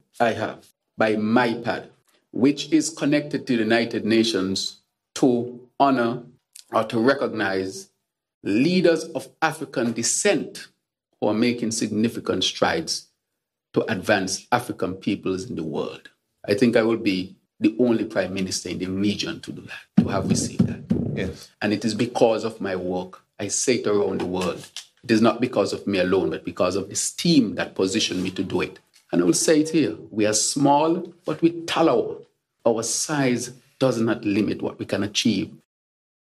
Prime Minister, the Hon Dr. Terrance Drew, has been nominated for the 2025 Lifetime Achievement Award and Tribute by the Most Influential People of African Descent or MIPAD which is affiliated with the United Nations. PM Drew made this statement: